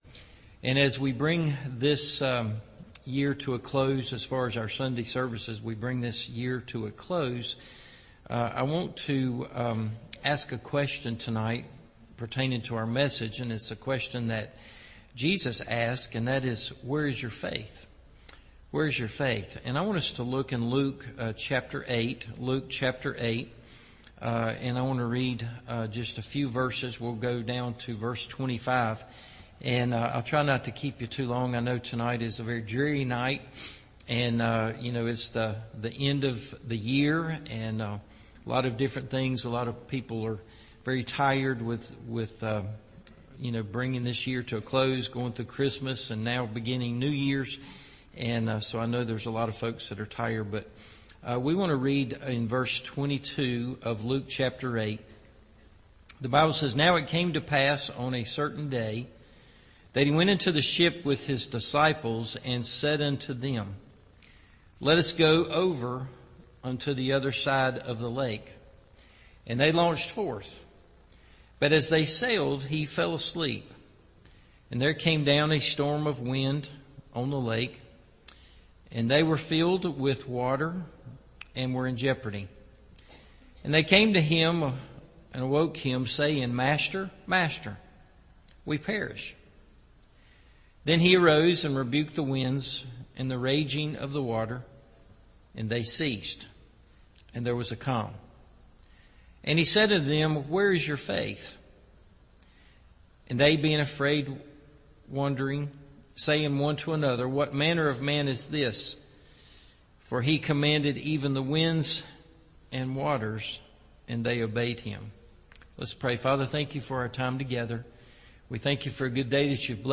Where Is Your Faith? – Evening Service